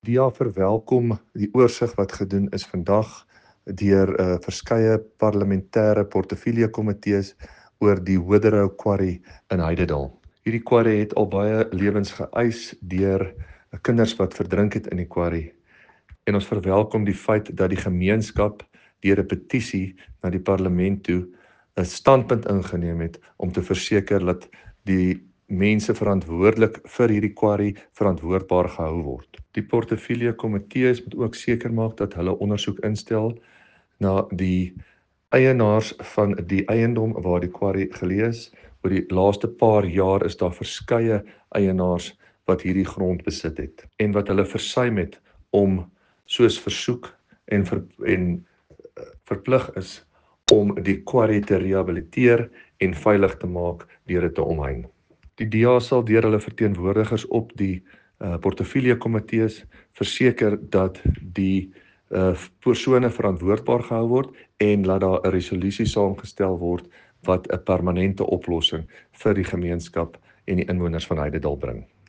Afrikaans soundbites by David van Vuuren MPL, and image here